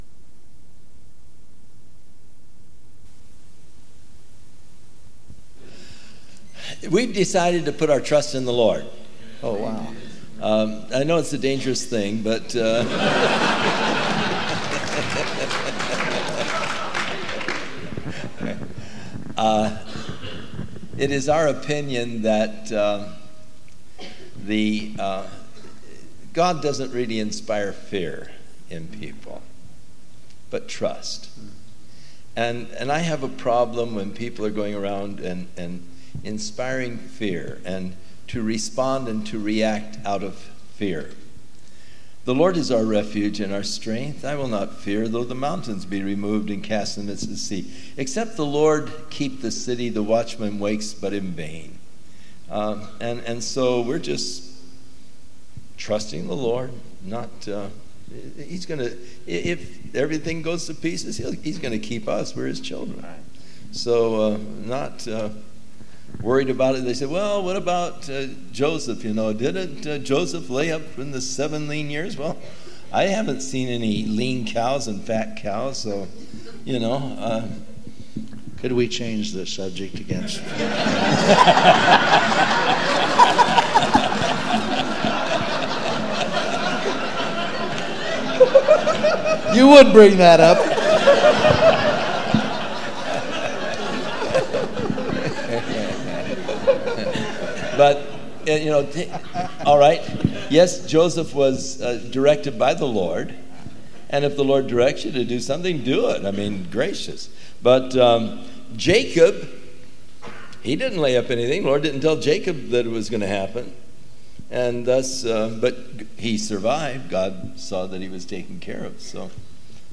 1999 DSPC: Session 6 – Panel Q&A
Series: 1999 DSPC Conference: Pastors & Leaders